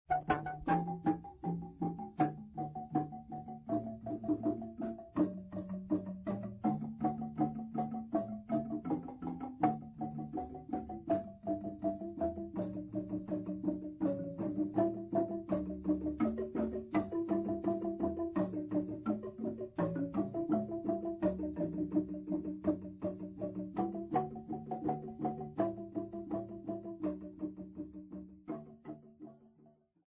Abafana Benhlonipho
Choral music South Africa
Hymns, Zulu South Africa
Africa South Africa Manguzi, KwaZuku-Natal sa
field recordings
Religious song with Marimba accompaniment.